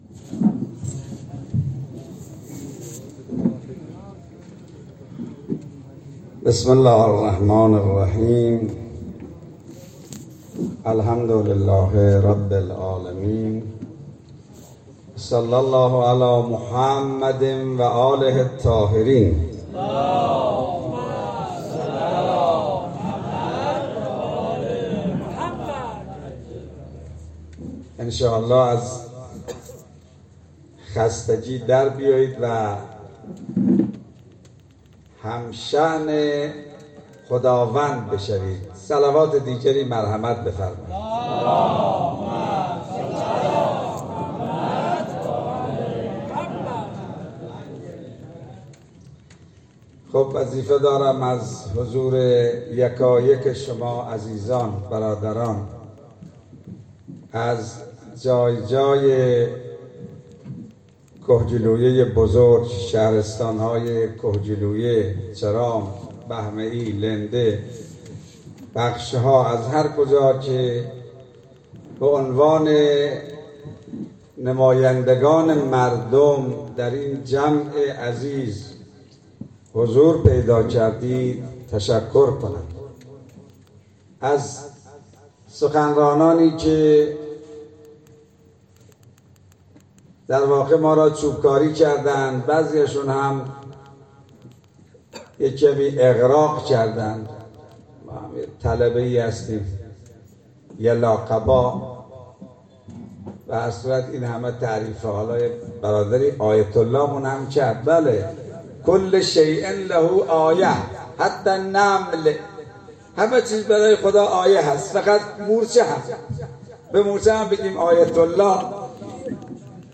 فعالیت انتخاباتی در شهرستان کهگیلویه شتاب گرفت. حجت الاسلام و المسلمین سید علی محمد بزرگواری یکی از کاندیداهای احتمالی انتخابات مجلس آینده شهرستان‌های کهگیلویه، بهمئی، چرام و لنده اولین نشست انتخاباتی خود را در منزل یکی از هوادارانش در میدان جهاد شهر دهدشت برگزار شد.
در این نشست، بعضی افراد به نقطه نظرات خود پرداختند.